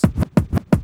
Shady Turntables.wav